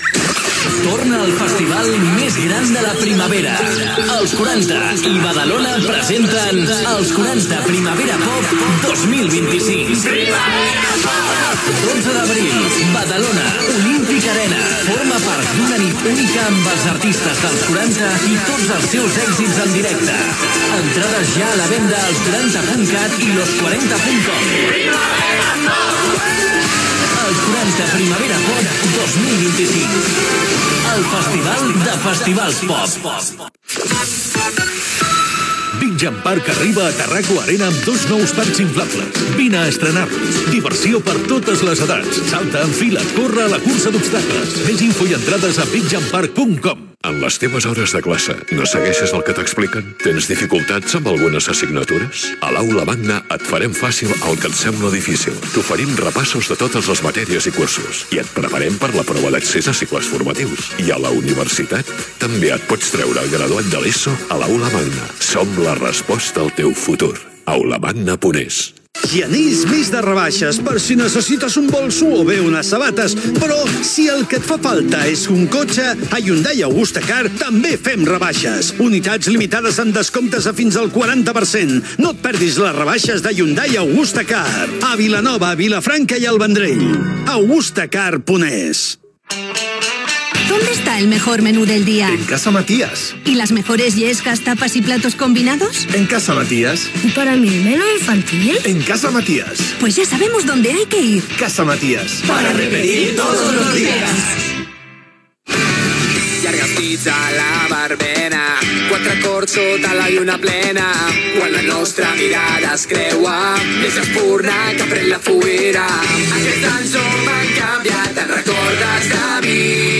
publicitat, tema musical, indicatiu Gènere radiofònic Musical